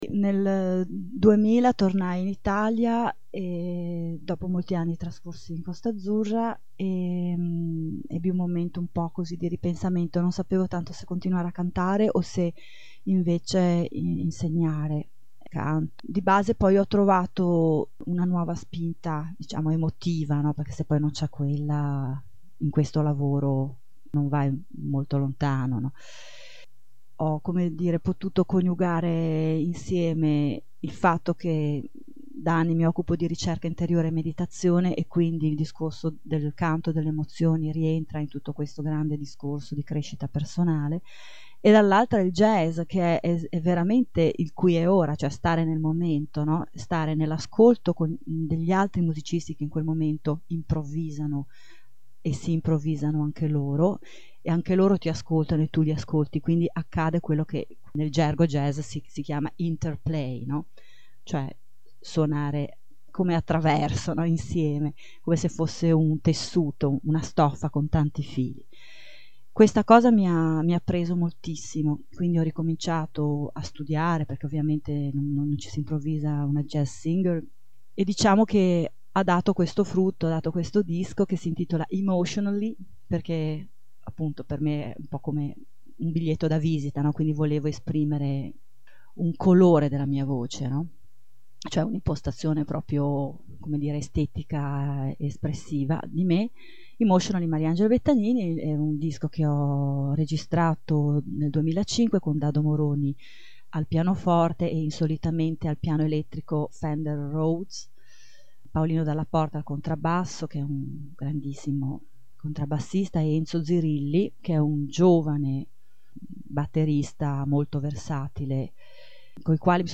Intervista a Radio Babboleo